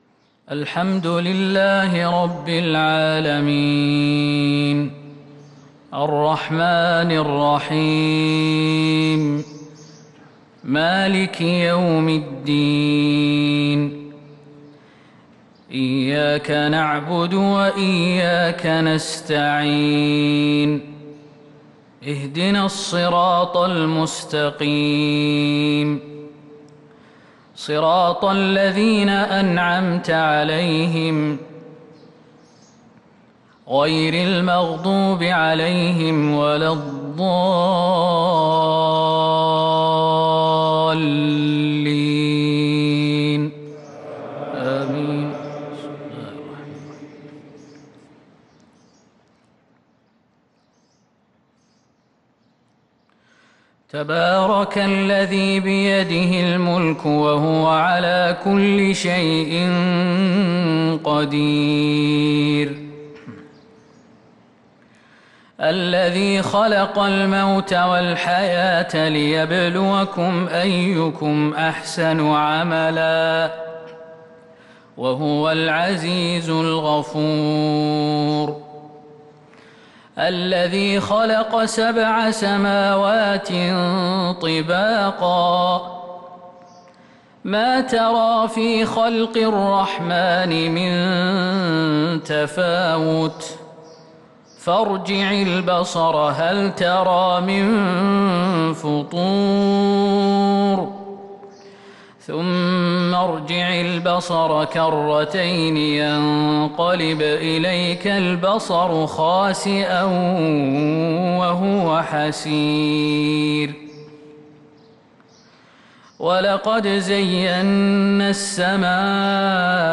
فجر الجمعة 7-9-1443هـ من سورة الملك | fajr prayer from surat AI-Mulk 8-4-2022 > 1443 🕌 > الفروض - تلاوات الحرمين